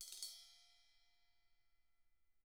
Index of /90_sSampleCDs/ILIO - Double Platinum Drums 2/Partition H/CYMBALRUFFSD